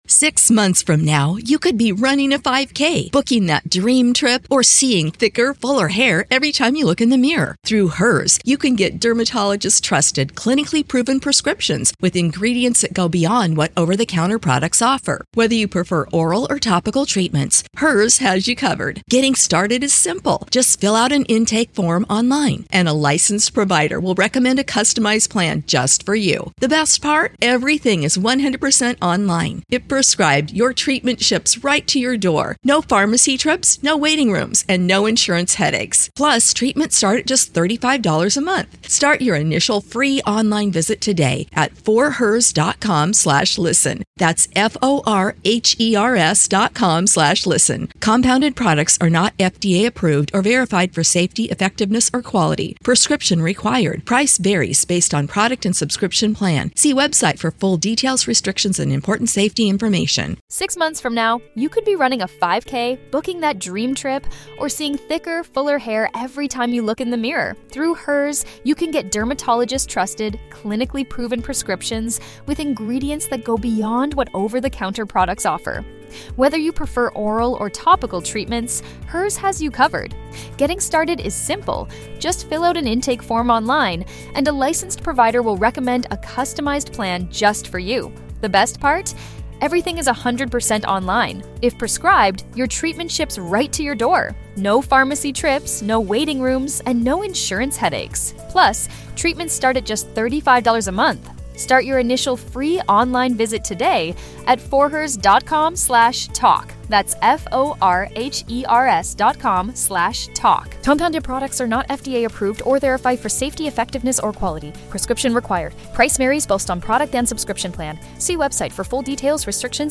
Deepcon 23 – FantascientifiCast